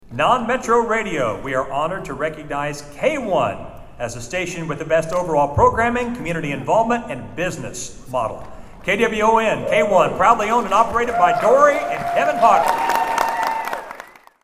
It was a huge night Thursday for your Bartlesville Radio stations at the Oklahoma Association of Broadcasters Awards Ceremony in Tulsa.